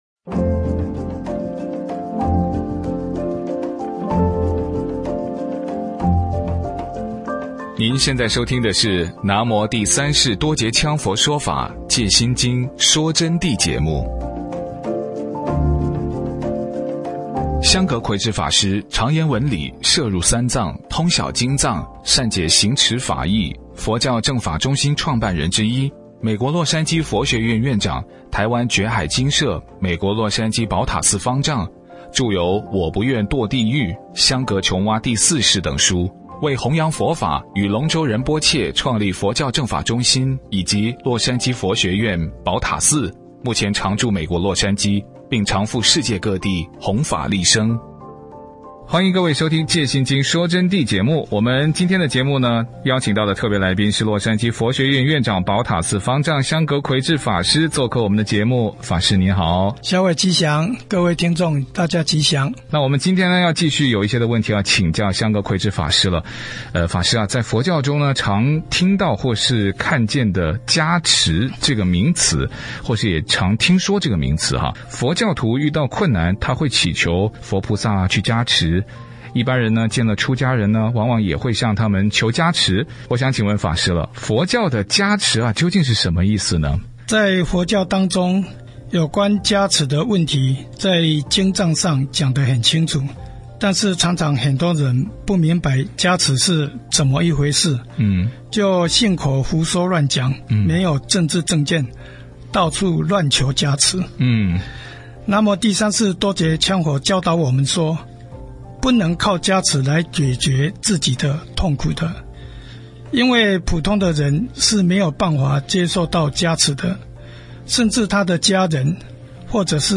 佛弟子访谈（三十）佛教的加持是什么意思？加持从何而来？如何获得加持？